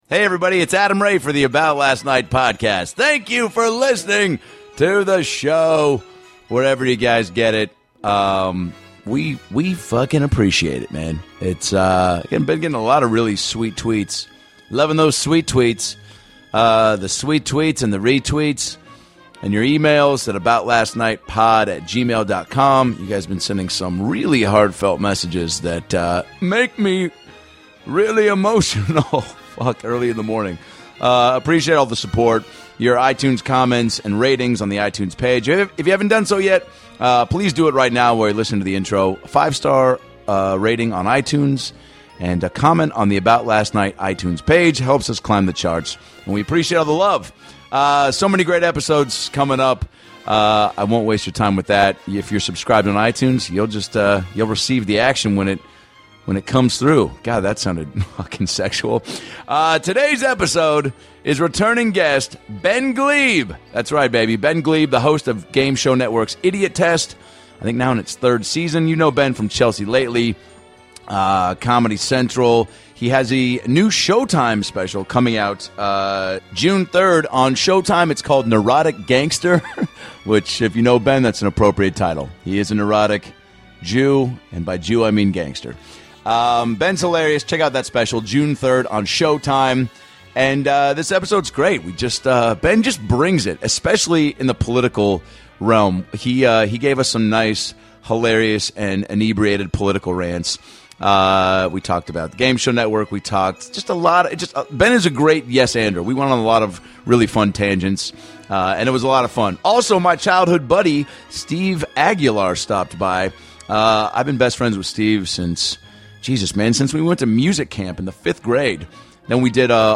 This episode was recorded at the Hollywood Improv in Los Angeles, CA and is jam packed with laughs from top to bottom.